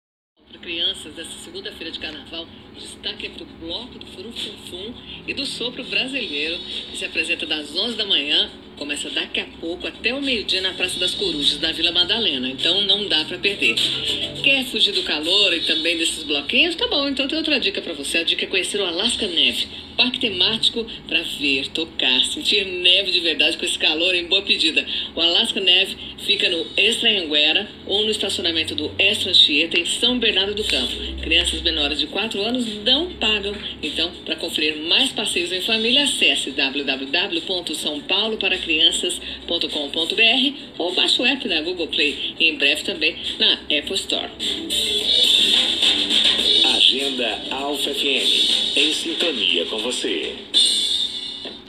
Toda sexta-feira você pode acompanhar as melhores dicas de passeios em família no “Boletim São Paulo para Crianças”, na seção de Cultura da Alpha FM!